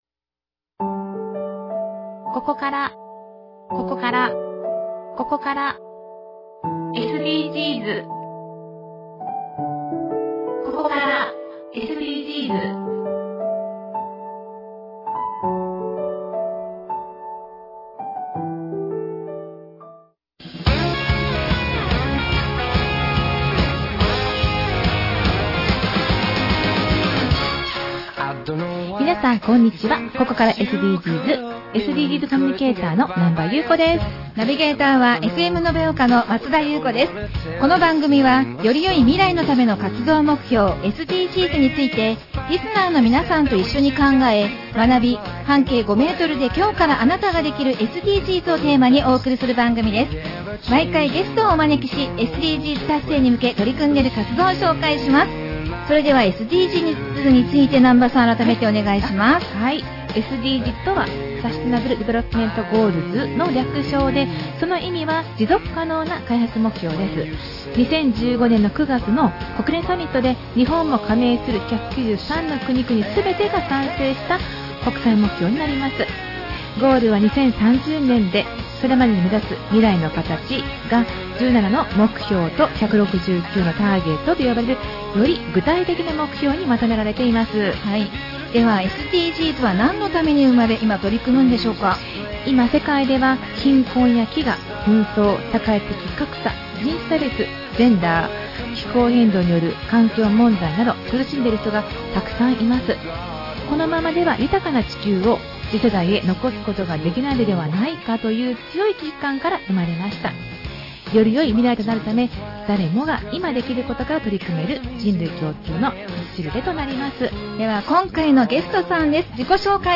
特別番組